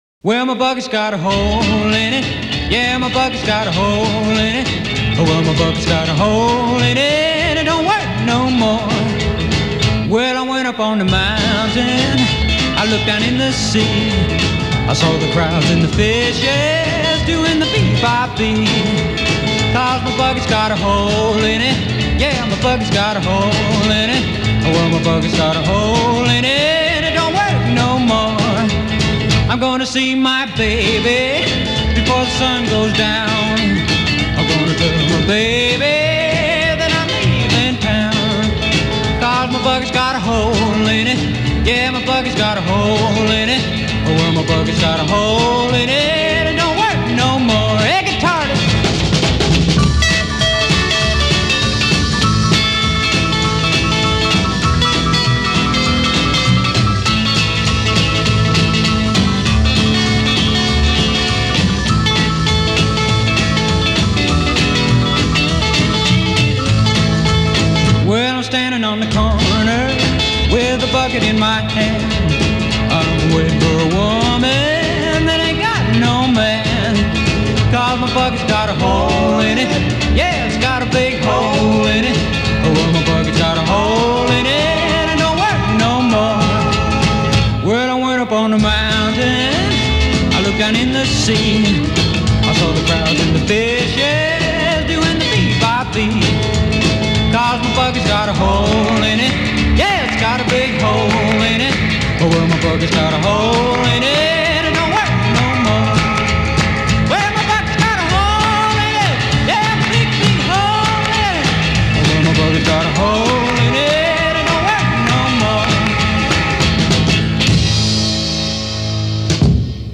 Classic rockabilly tracks
50 original recordings digitally remastered